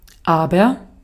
Ääntäminen
US : IPA : [haʊ.ˈɛv.ɚ]